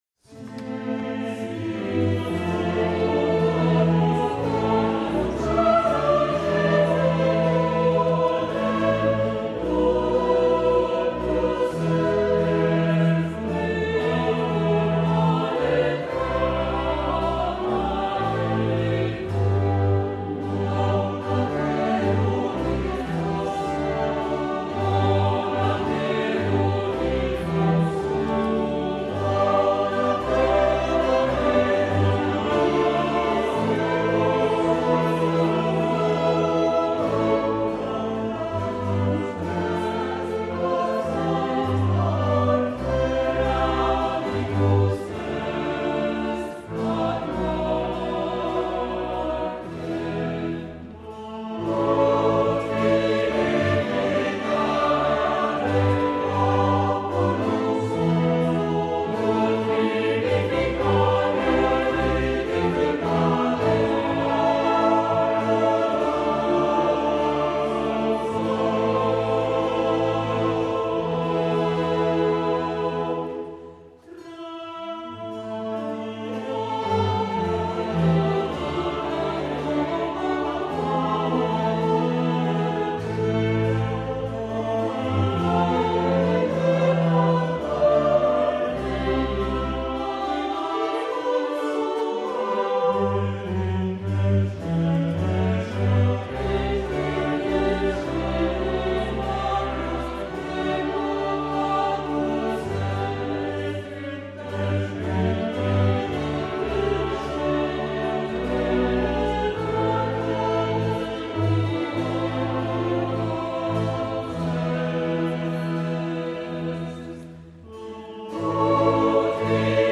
primo violino
secondo violino
viola
violoncello
contrabbasso
cembalo
GenereCori